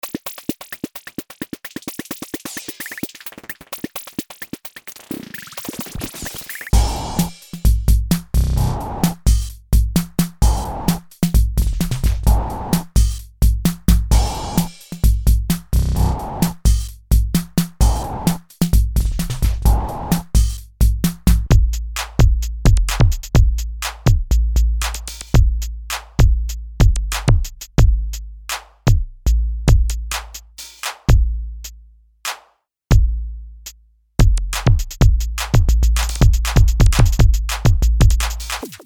Rhythm Synthesizer (1999)
Advanced drum machine, a "rhythm synthesizer" based on a mix of virtual analog modeling synthesis and PCM samples.
Class: Drum machine